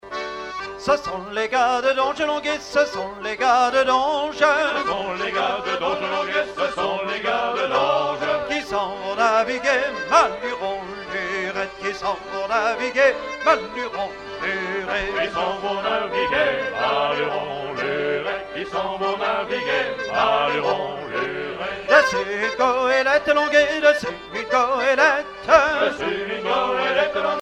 danse : ronde à trois pas
Genre laisse
Pièce musicale éditée